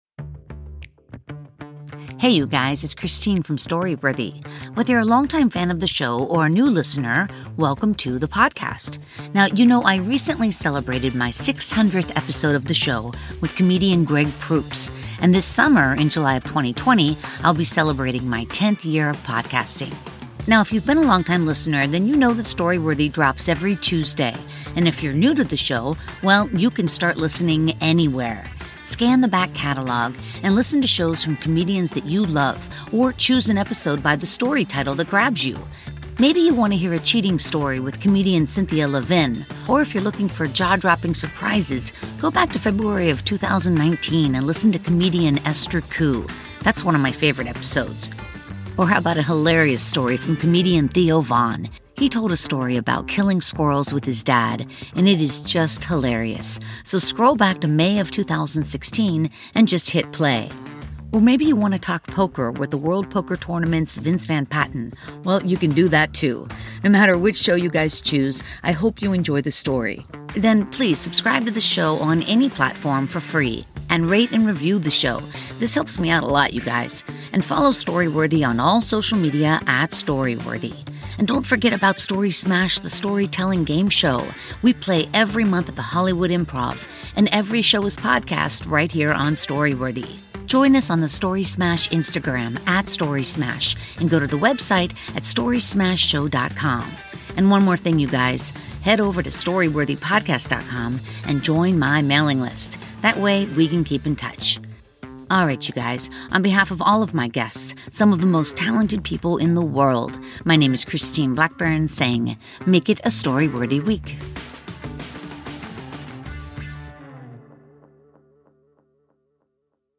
Hollywood's most interesting comedians and writers tell true, personal stories on Story Worthy!